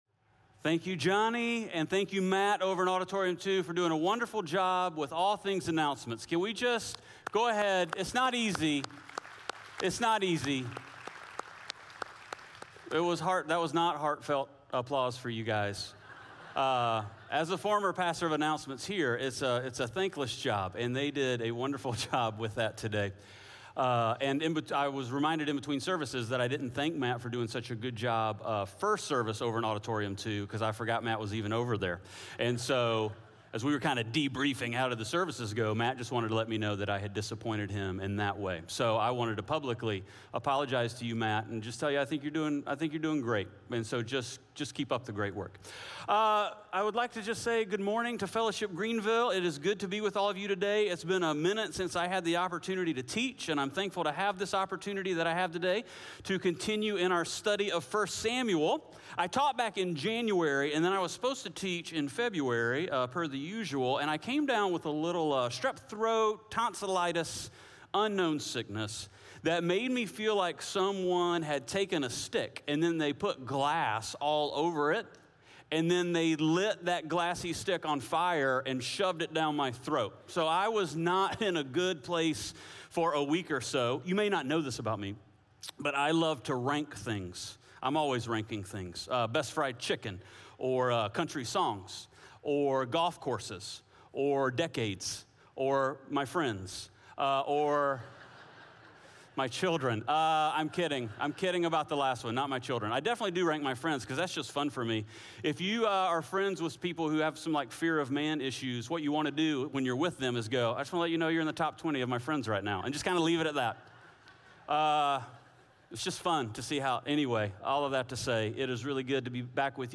Audio Sermon Notes (PDF) Ask a Question SERMON SUMMARY 1 Samuel 12 is a testimony to Samuel’s leadership.